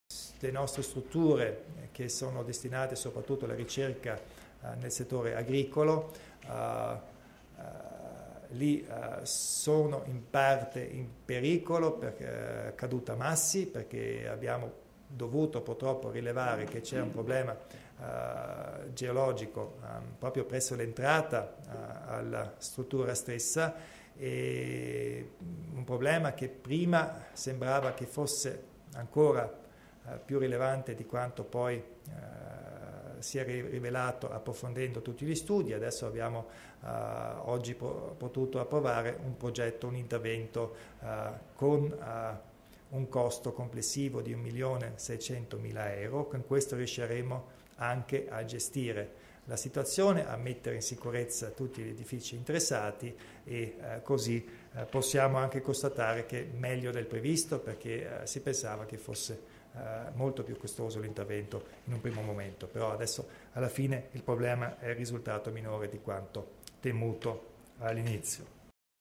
Il Presidente Kompatscher spiega gli interventi urgenti a Laimburg